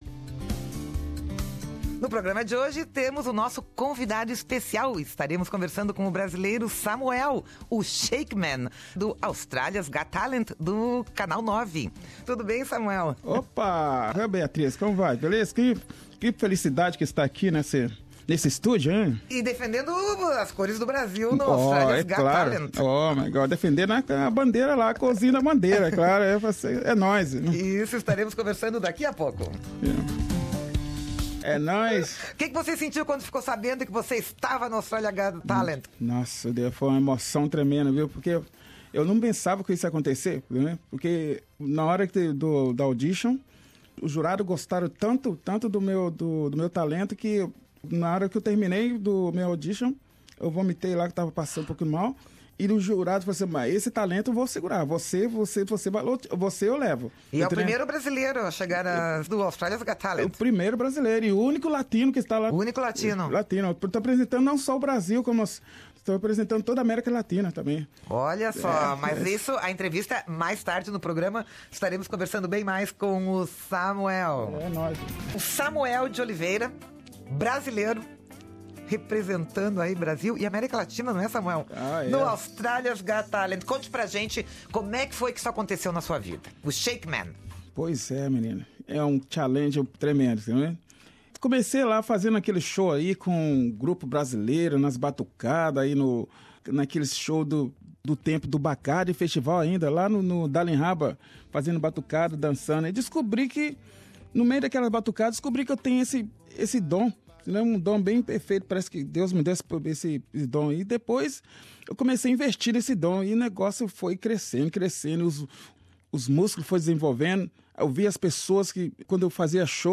Os jurados desta edição do Australia's Got Talent são Kelly Osbourne, Ian "Dicko" Dickson, Sophie Monk e Eddie Perfect. Ouça aqui a entrevista